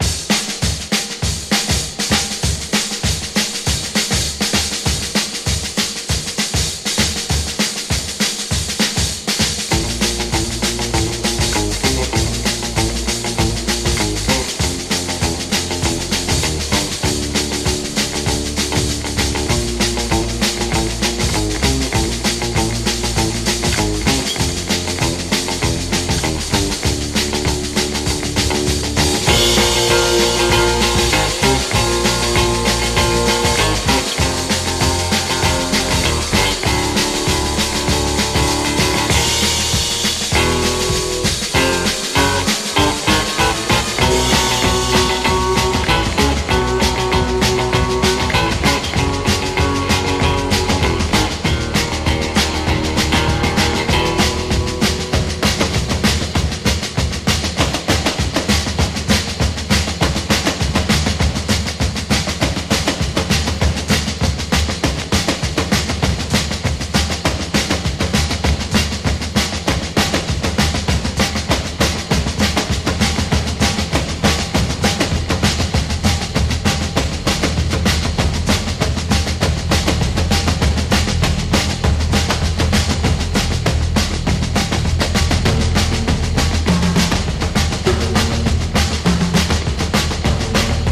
ファンキー・ドラム・インストロ・クラシック！